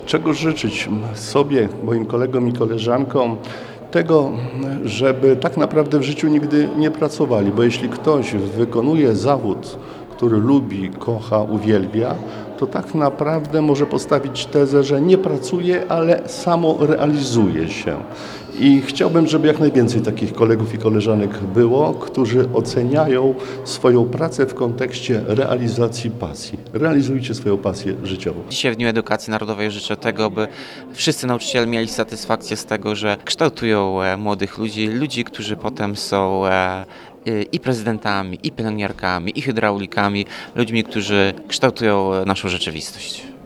– Realizujcie swoje pasje życiowe, miejcie z nich satysfakcję, a także bądźcie ludźmi, którzy kształtują naszą rzeczywistość – to życzenia warmińsko-mazurskiego kuratora i prezydenta Ełku.